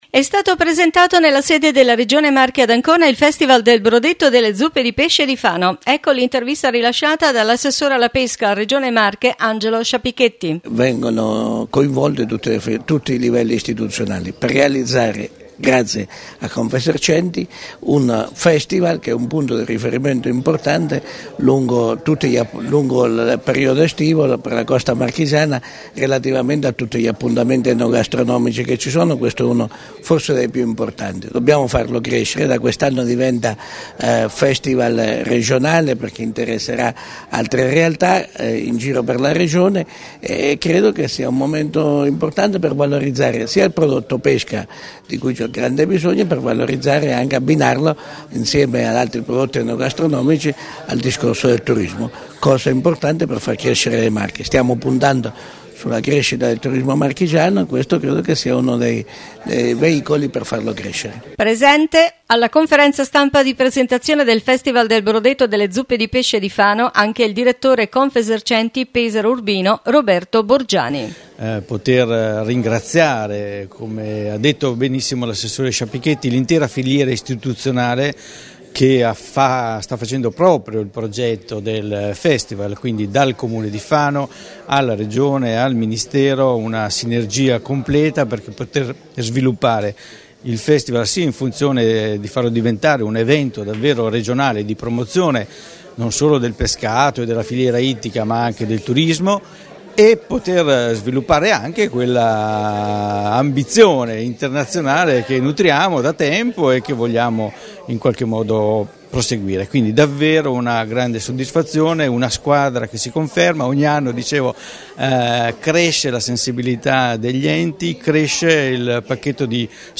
New Radio Star | Notizie Regione 3 Luglio 2018